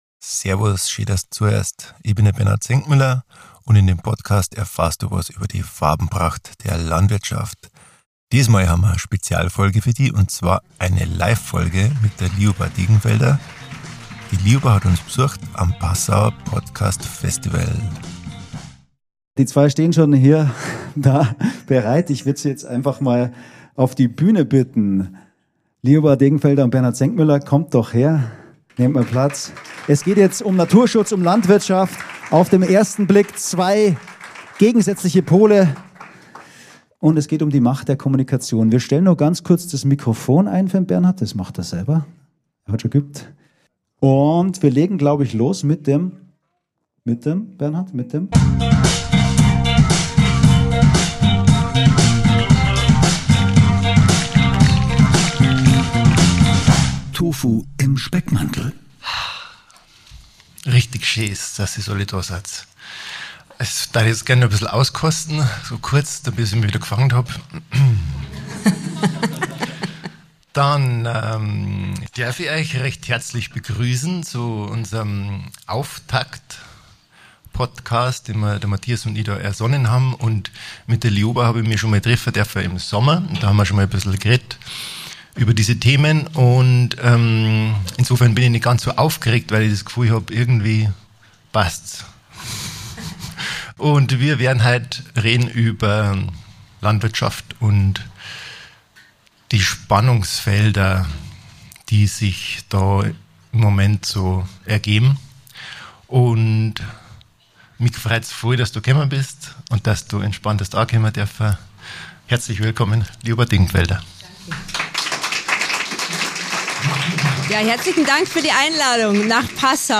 Außerdem hören wir Aufnahmen und Klänge, die klar machen: Wiesen und Weiden sind Lebensraum für eine Vielzahl von Pflanzen- und Tierarten und schützenswert!